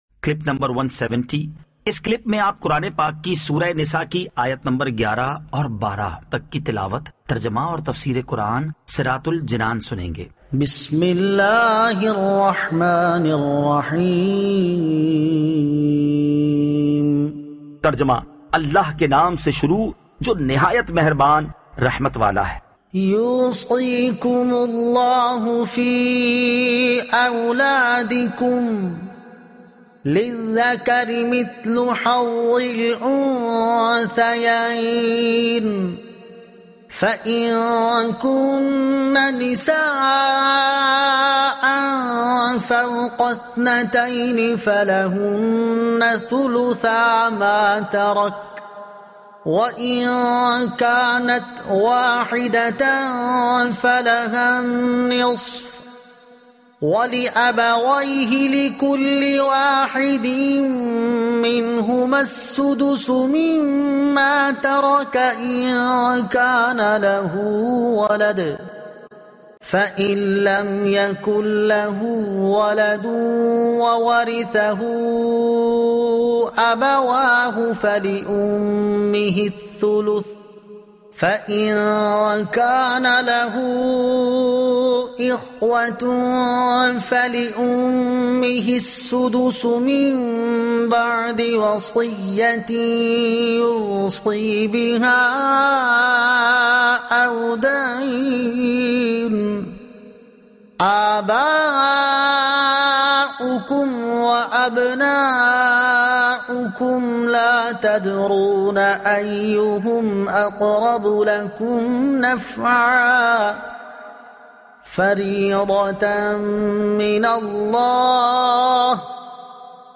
Surah An-Nisa Ayat 11 To 12 Tilawat , Tarjuma , Tafseer